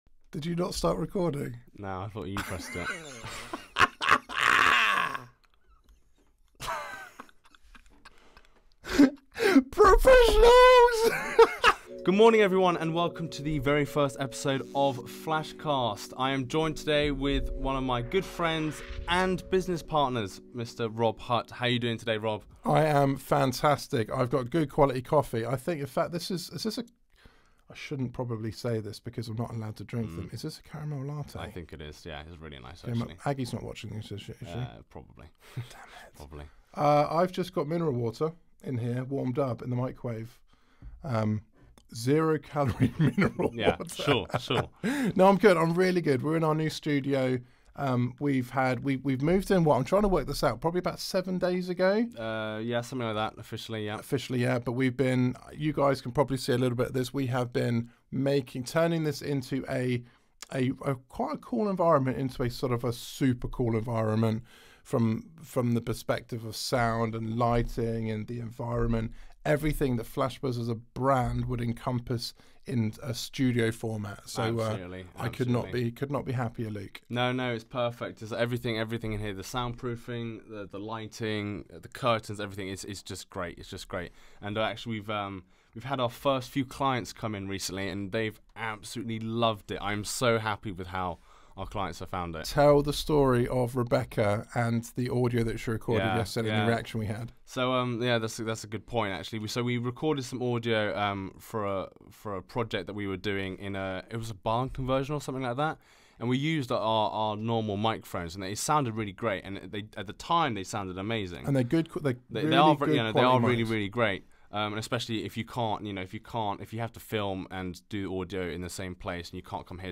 Here's our first podcast from our new video & podcast studio in Banbury, Oxfordshire. To celebrate the move we thought we'd discuss the pros and cons of home/office vs studio!